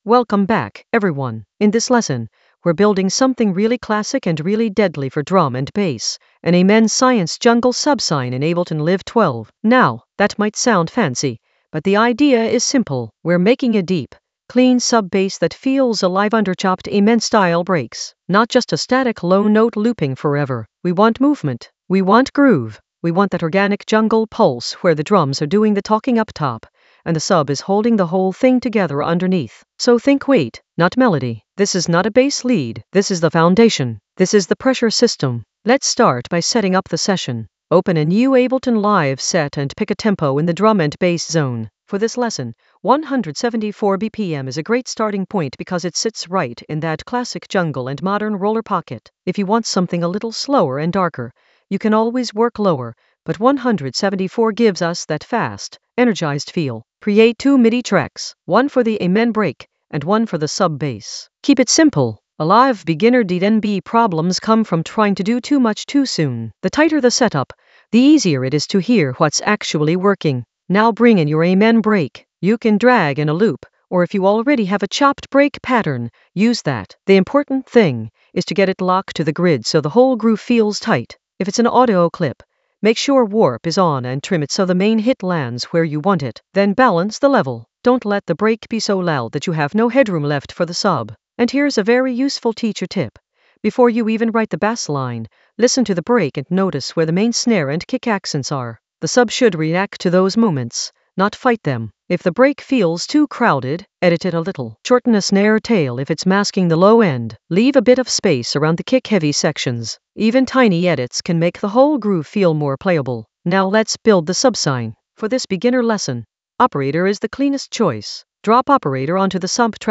An AI-generated beginner Ableton lesson focused on Amen Science jungle subsine: humanize and arrange in Ableton Live 12 in the Sound Design area of drum and bass production.
Narrated lesson audio
The voice track includes the tutorial plus extra teacher commentary.